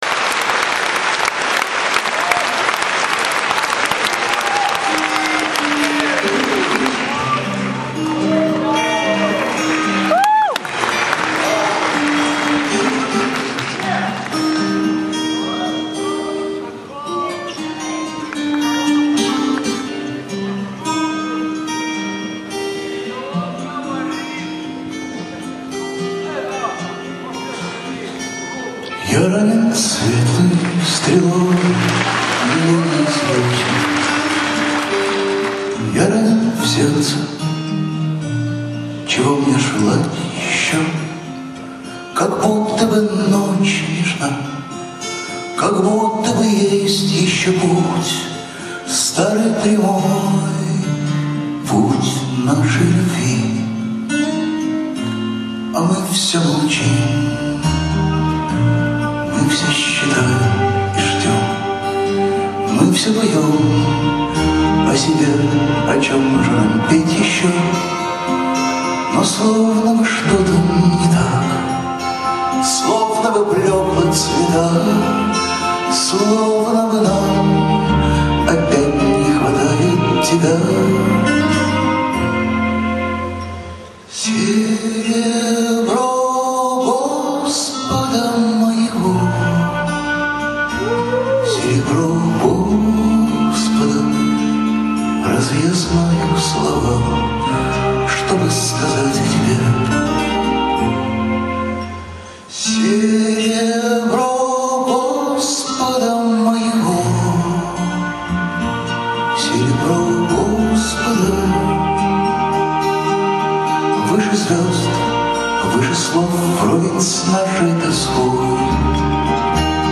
4. 2004-BKZ_Oktyabrskiy